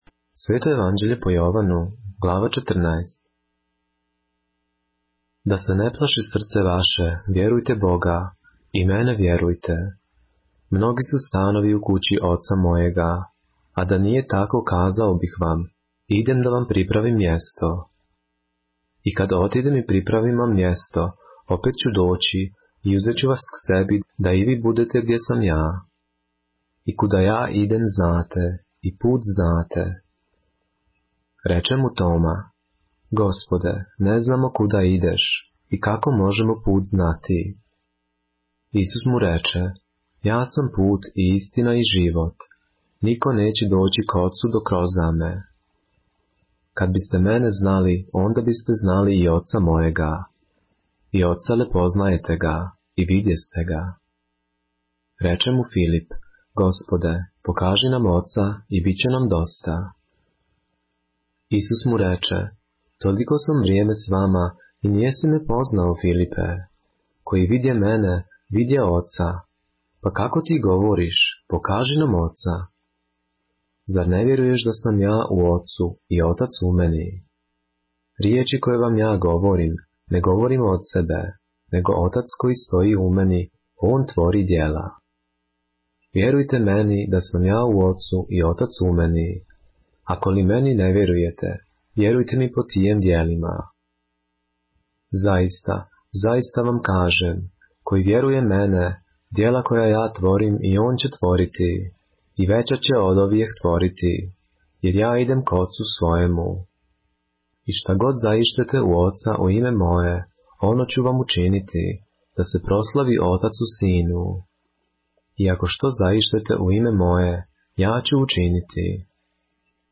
поглавље српске Библије - са аудио нарације - John, chapter 14 of the Holy Bible in the Serbian language